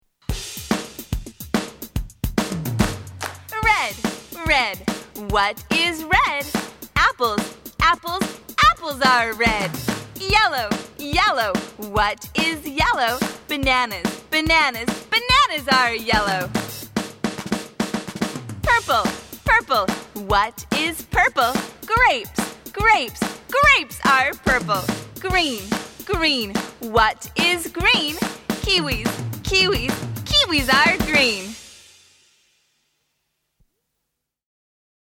チャンツ